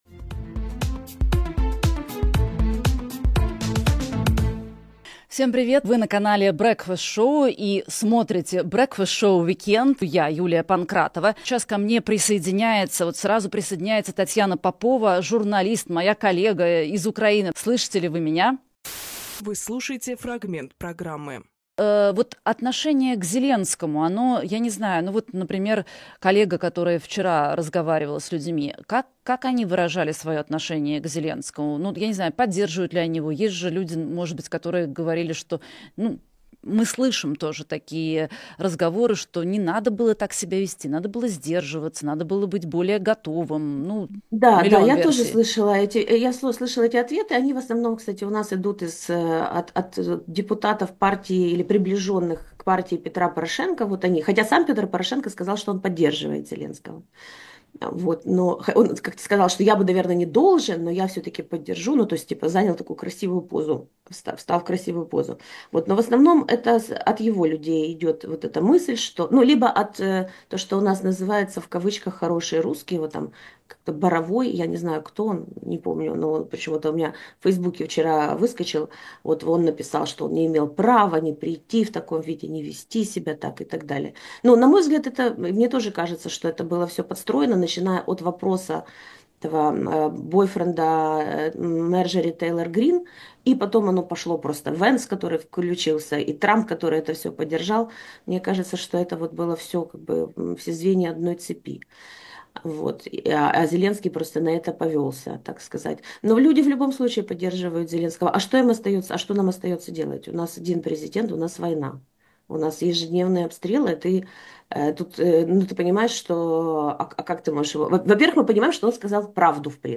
Фрагмент эфира от 02.03